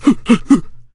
el_primo_atk_04.ogg